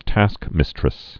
(tăskmĭstrĭs)